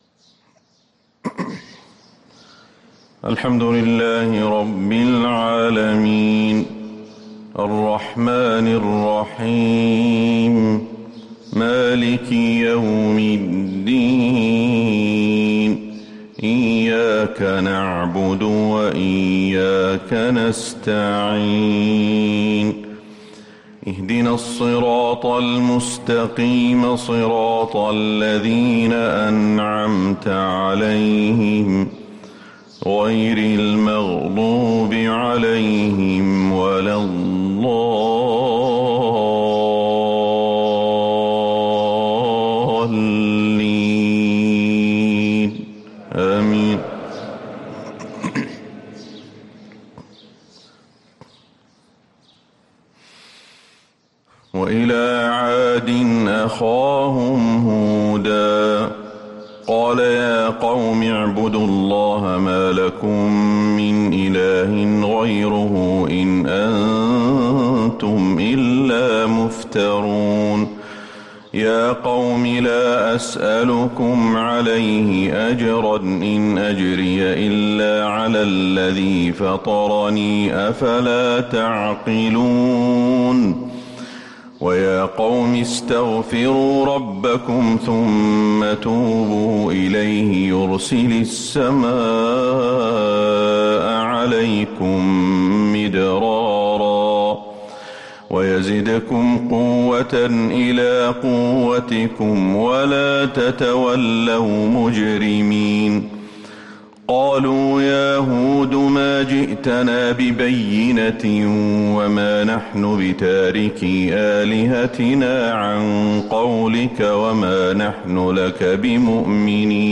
فجر السبت 7-2-1444هـ من سورة هود | Fajr prayer from Surah Hud 3-9-2022 > 1444 🕌 > الفروض - تلاوات الحرمين